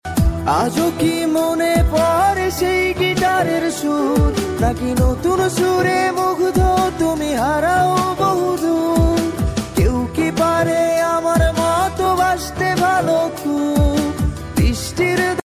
গিটারের সুর